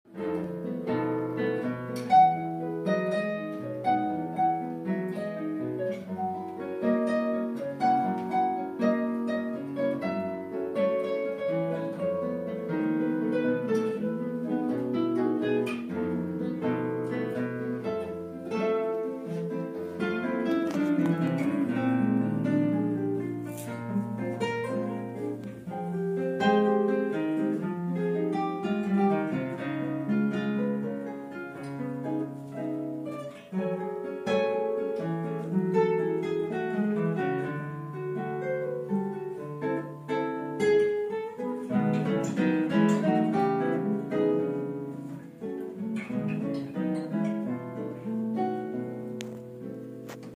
Live aus das Ledo-Konzert
Einmal jährlich kommt Live Music Now (LMN) nach Ledo für ein Konzert mit musikalischen Überraschungen.
Dieses Mal erklingen Gitarrenstücke aus der reichen musikalischen Landschaft Lateinamerikas bei uns im Gemeinschaftsraum.
Die beiden Gitarristinnen
von den mitreißenden Rhythmen des argentinischen Tangos über die melodischen Klänge der brasilianischen Bossa Nova bis hin zur erdigen Volksmusik aus Mexiko.